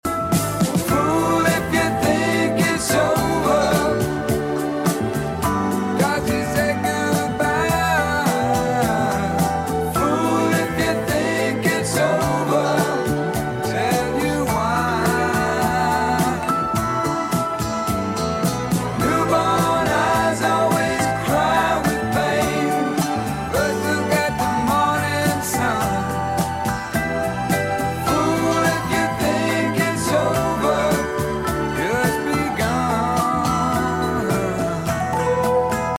smooth vocals
soulful instrumentation